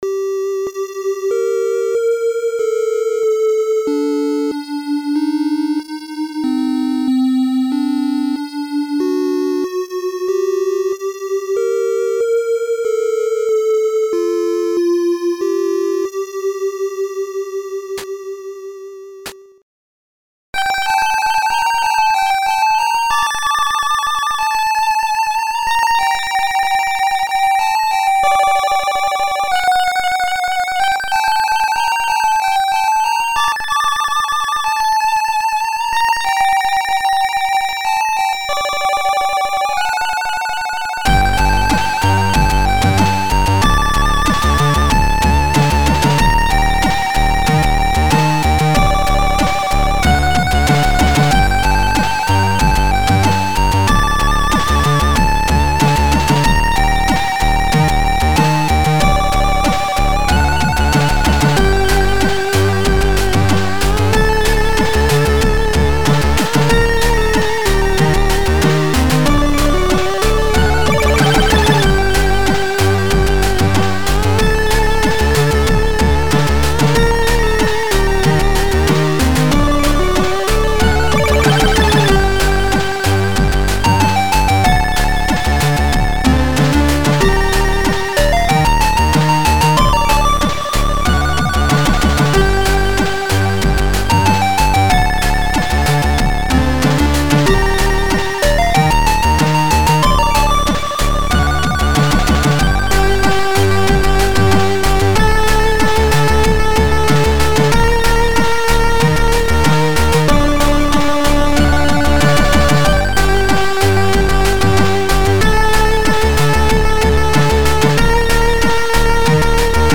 chip